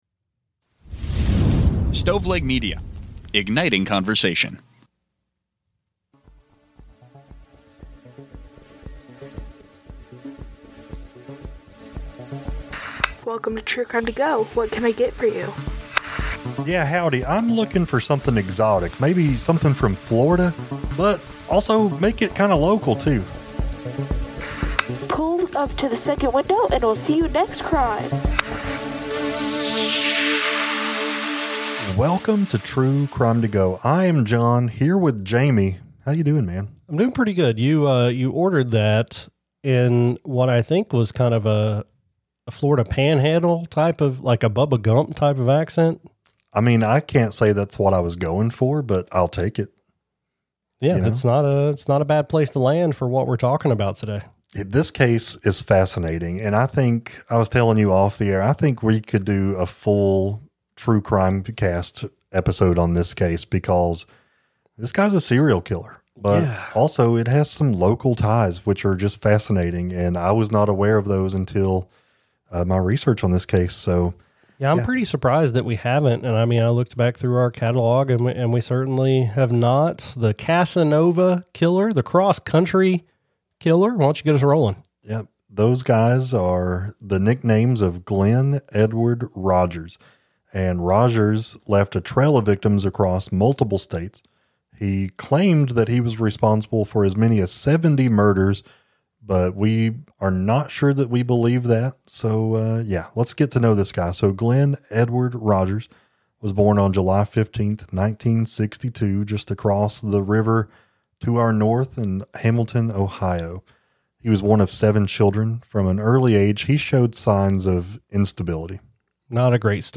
Society & Culture, Personal Journals, Documentary, True Crime